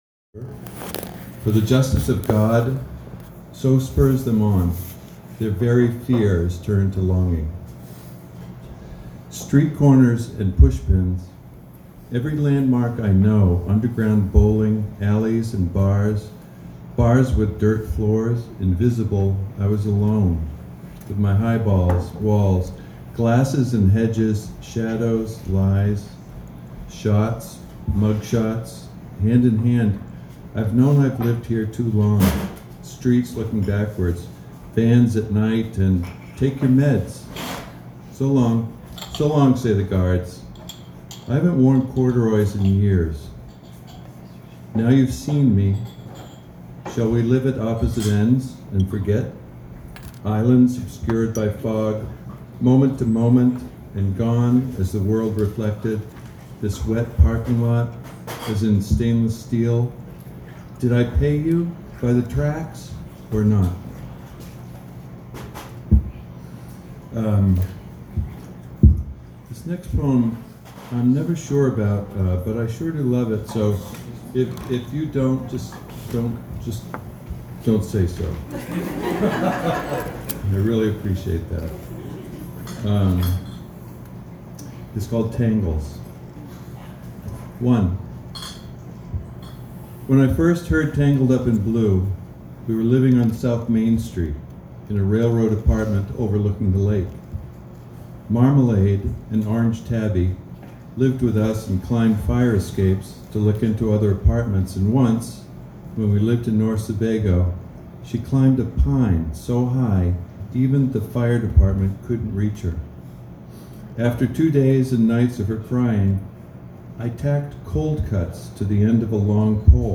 poetry.m4a